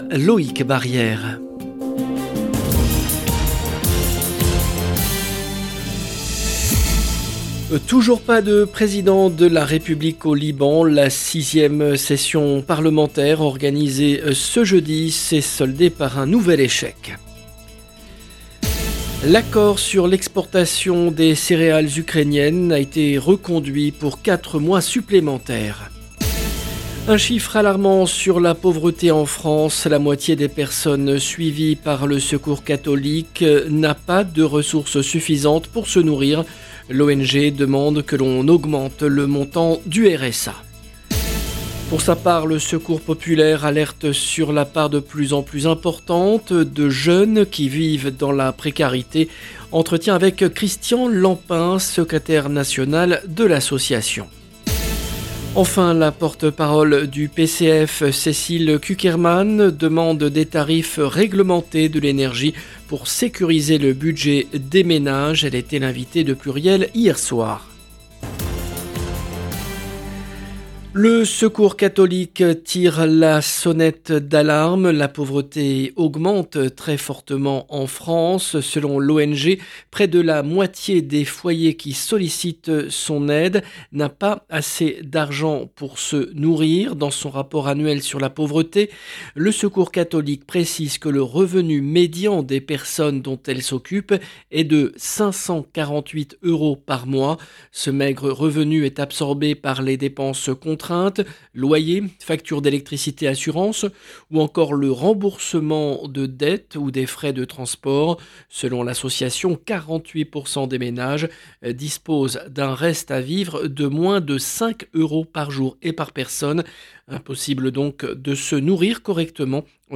Pour sa part le Secours Populaire alerte sur la part de plus en plus importante de jeunes qui vivent dans la précarité. Entretien